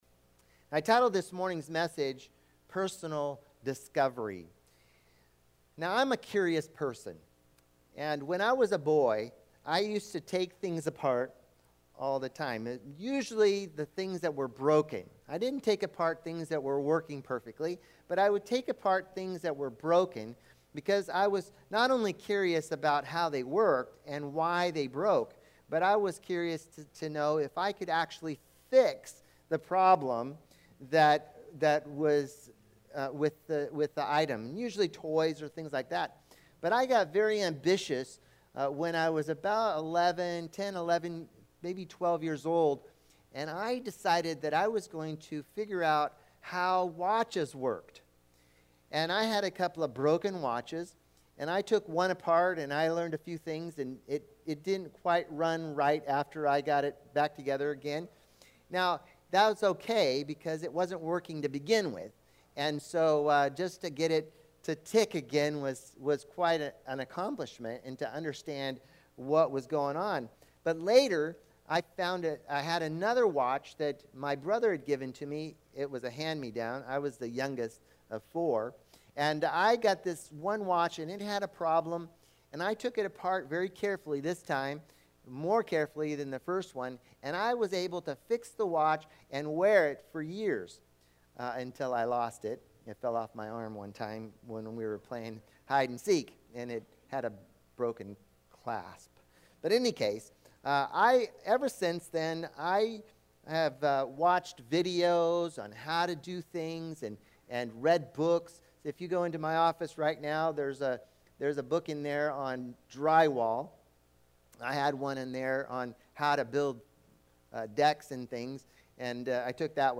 Bible Text: Luke 9:18-22 | Preacher